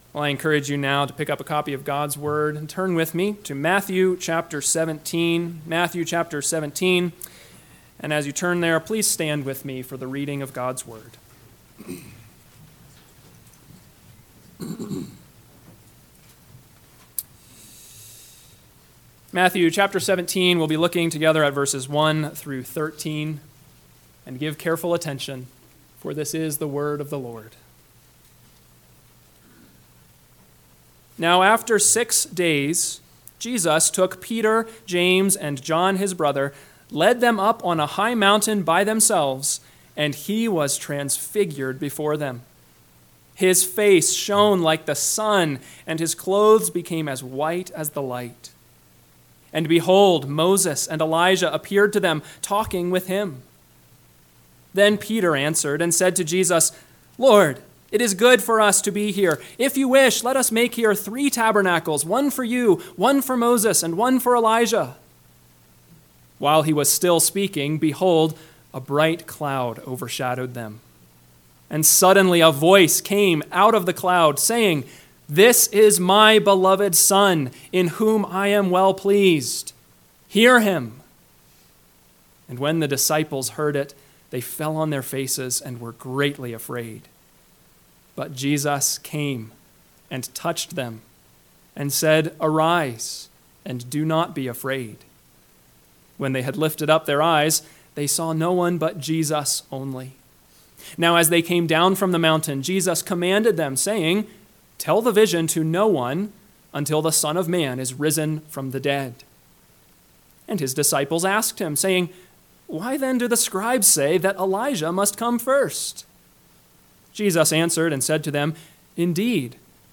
10/3/2024 – Rocky Mountain Presbytery Worship Service for the Fall 2024 Presbytery Meeting.